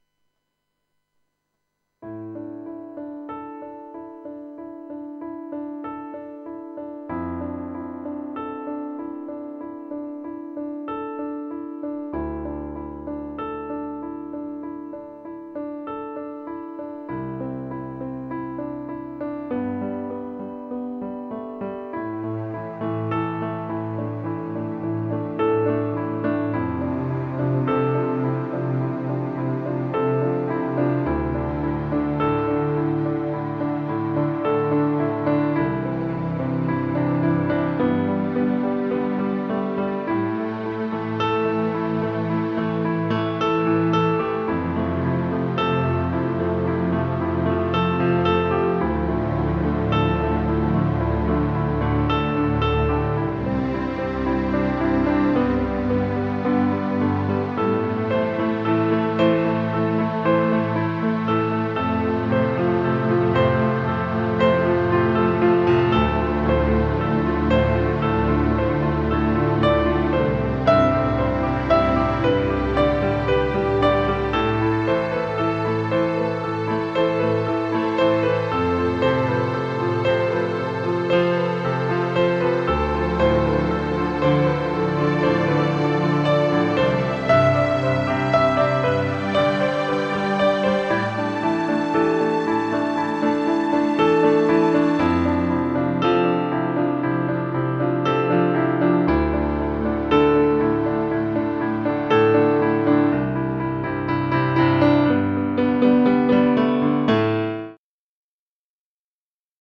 Žánr: Indie/Alternativa
Relaxační "easy listening" hudba na piano